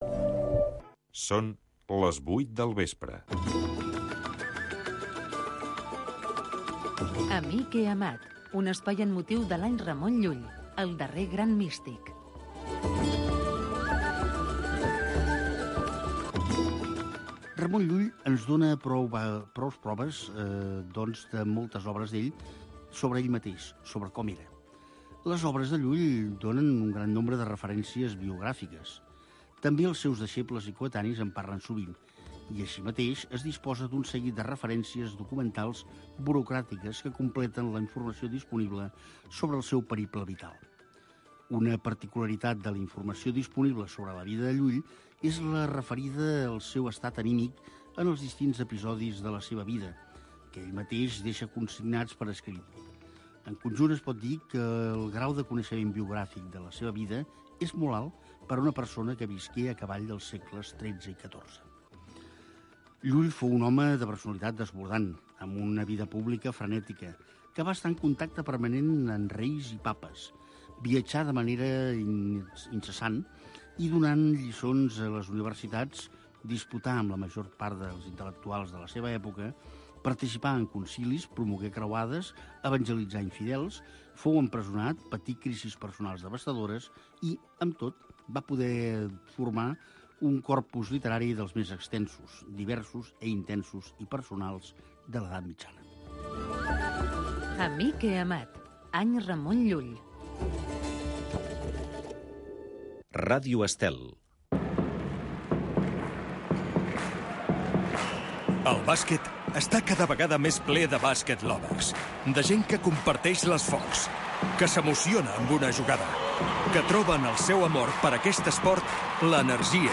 Plusvàlua. Un programa amb entrevistes i tertúlia sobre economia amb clau de valors humans, produït pel CEES